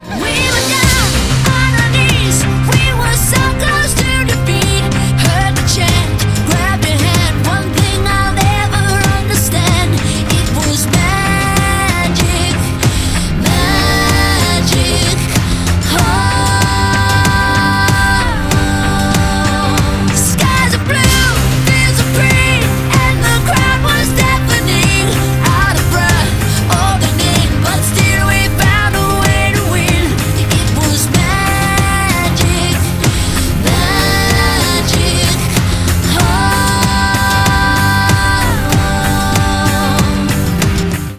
• Качество: 128, Stereo
громкие
зажигательные
заводные